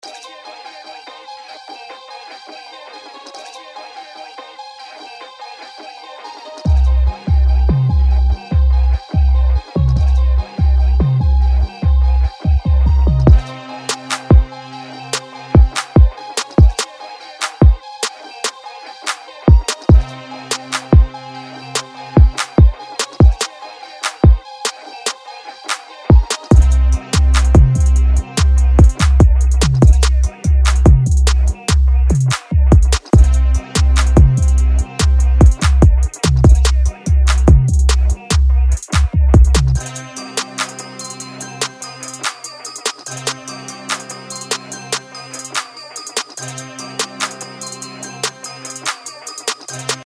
Tags: hiphop , trap , trap hop